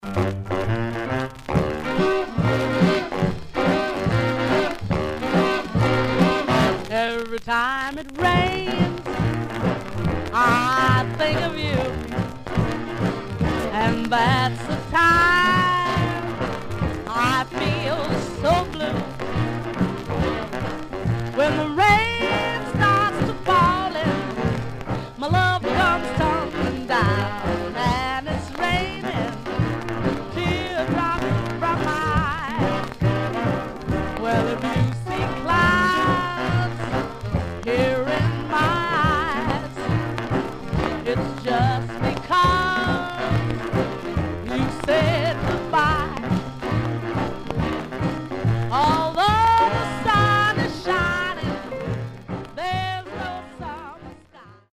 Much surface noise/wear
Mono
Rythm and Blues